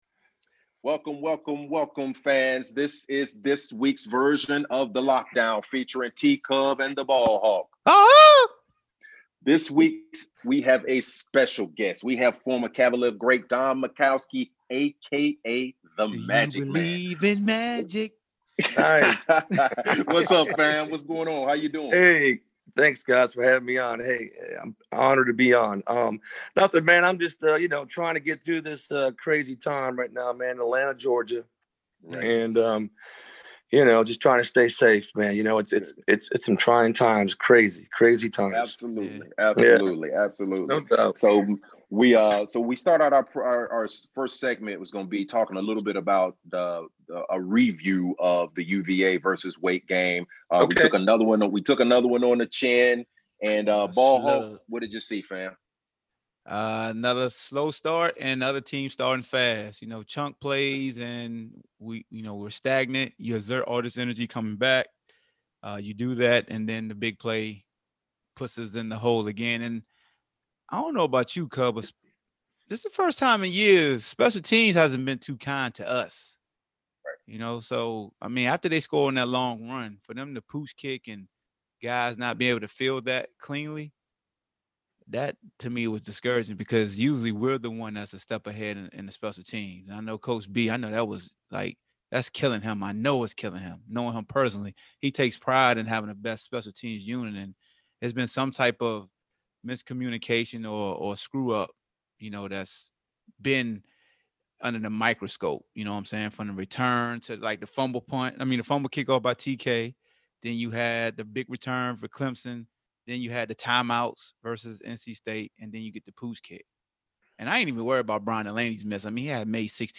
You don't want to miss this RARE interview.